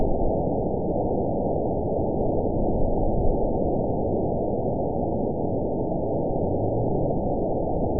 event 920093 date 02/22/24 time 11:38:35 GMT (1 year, 9 months ago) score 8.75 location TSS-AB05 detected by nrw target species NRW annotations +NRW Spectrogram: Frequency (kHz) vs. Time (s) audio not available .wav